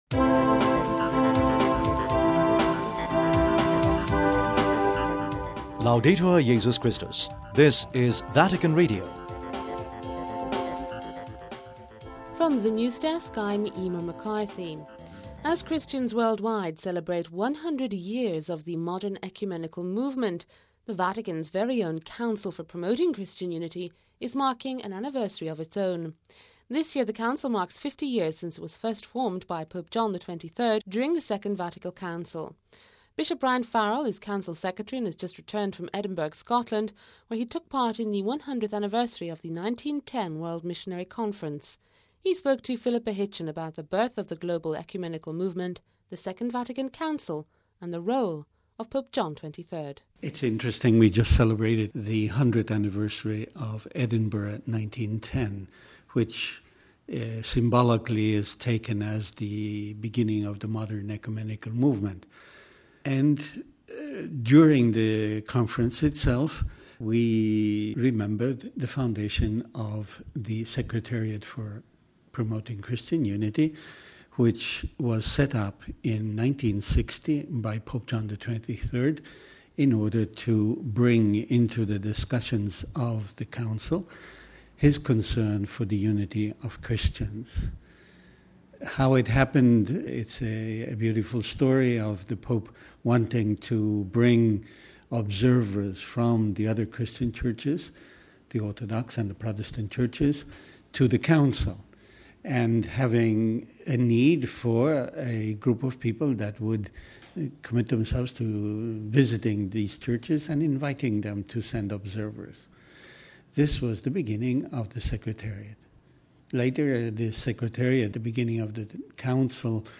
INTERVIEW OF THE DAY